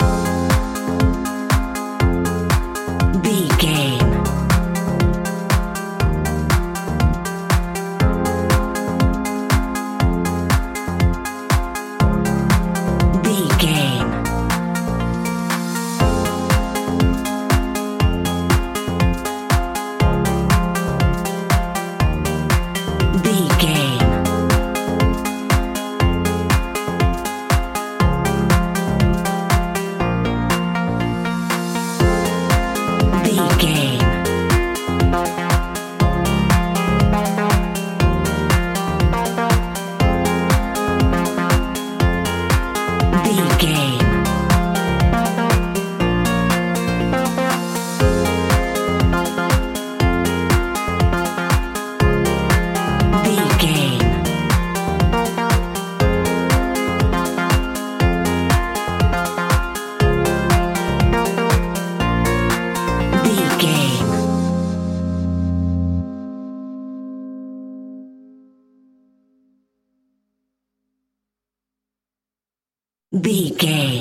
Aeolian/Minor
groovy
energetic
drum machine
synthesiser
funky house
disco
upbeat
funky guitar
clavinet
synth bass